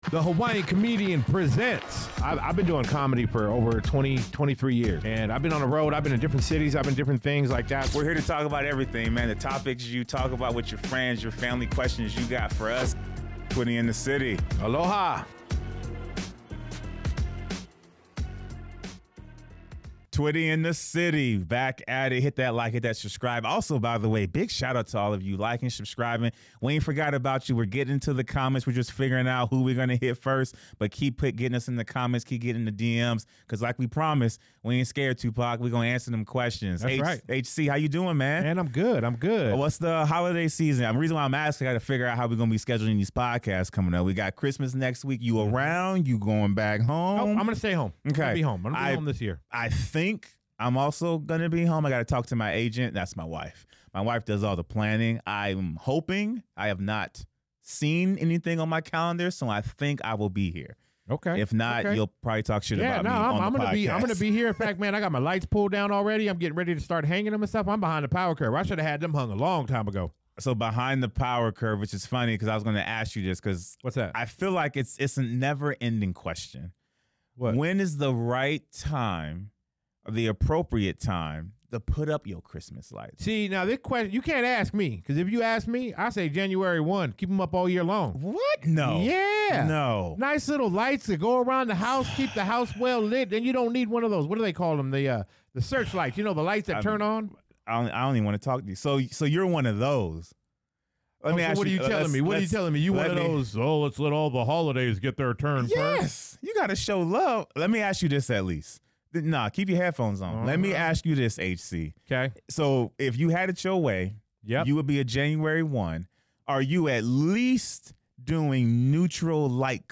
Join them for a lively discussion filled with laughs, festive spirit, and a bit of h